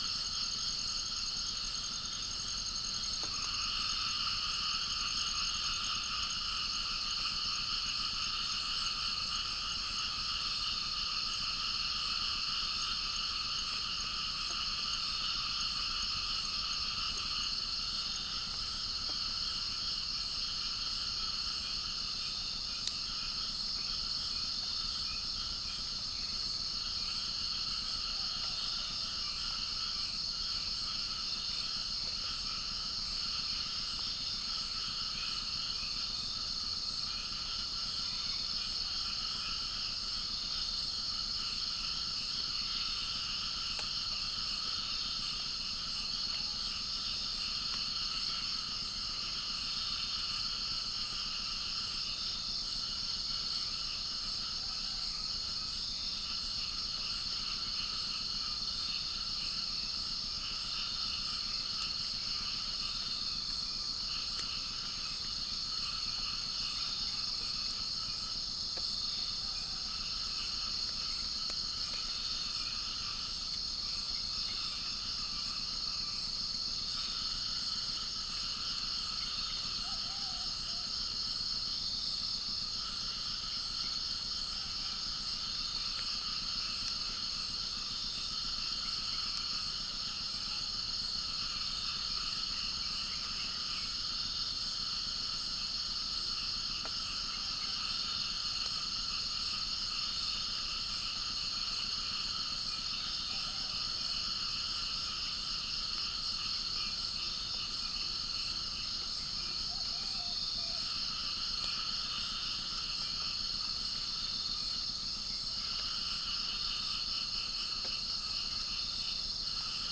Gallus gallus
Centropus bengalensis
Pycnonotus goiavier
Orthotomus sericeus
Prinia familiaris